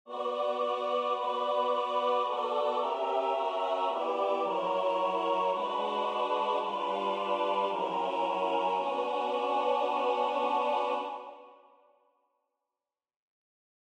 Key written in: B Minor
How many parts: 4
Type: Female Barbershop (incl. SAI, HI, etc)
All Parts mix: